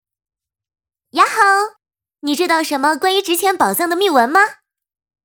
女声
亲切甜美